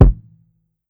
Dumb Kick.wav